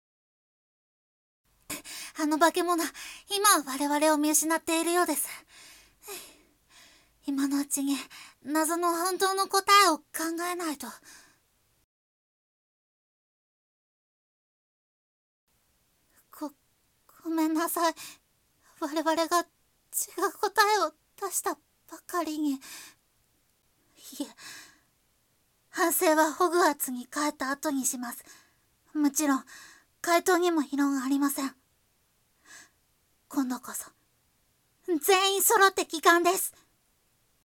🌨声劇②